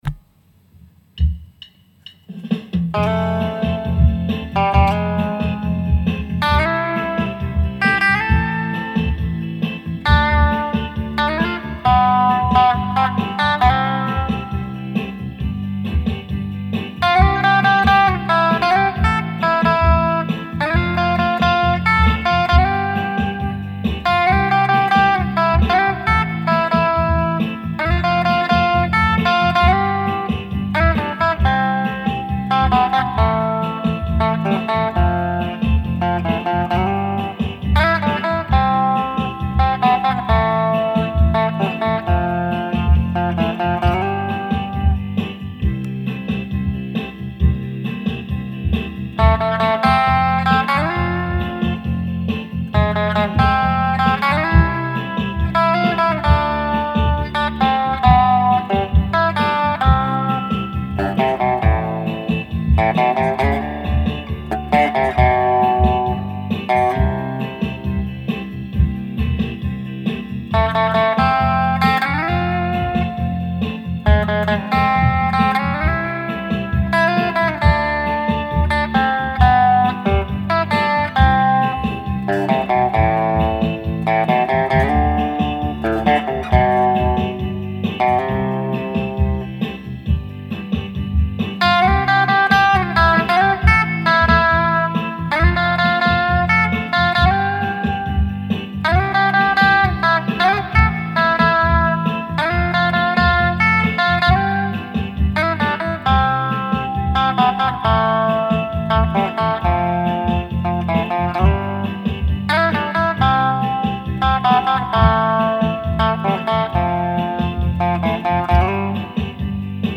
NOUS AVIONS 15 ANS (Twist